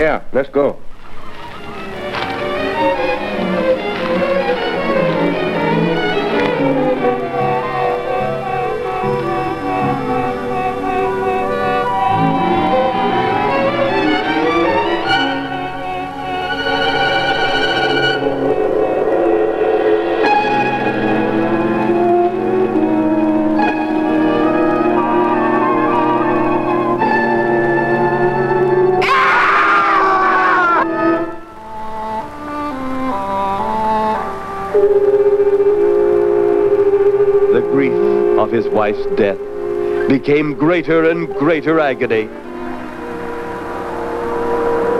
Stage & Screen, Soundtrack　USA　12inchレコード　33rpm　Mono